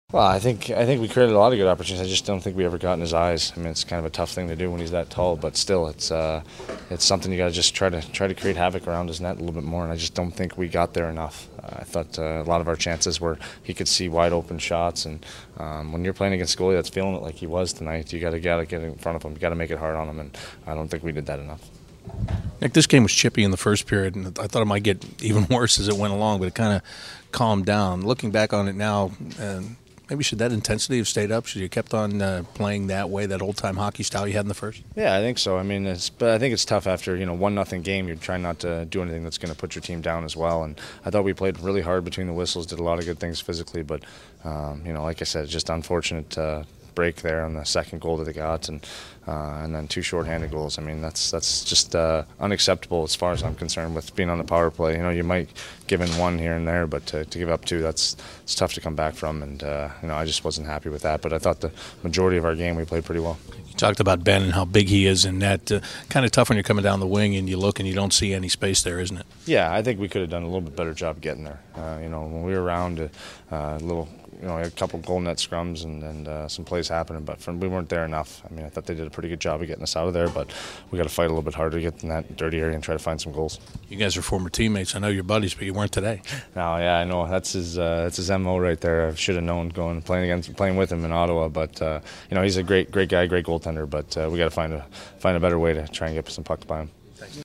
Nick Foligno Post Game Interview 3-13-16